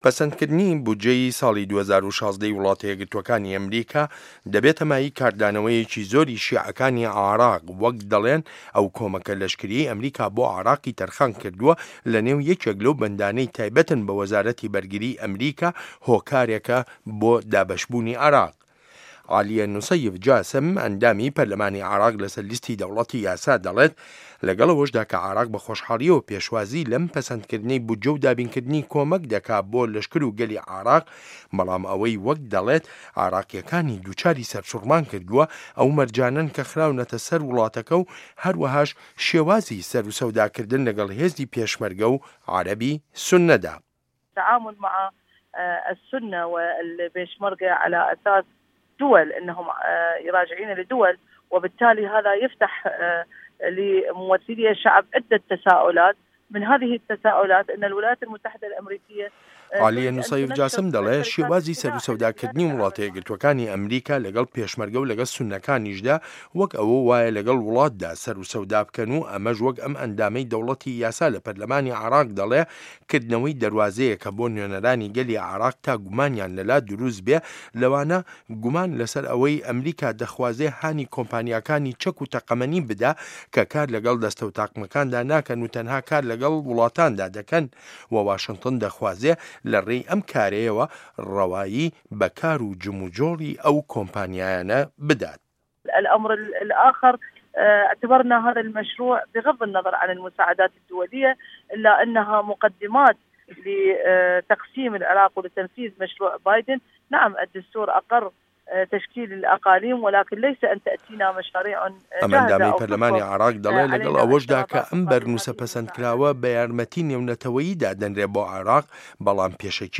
ڕاپۆرت له‌سه‌ر بنچینه‌ی لێدوانه‌کانی عالیه‌ نوسه‌یف جاسم